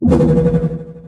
head_collect.ogg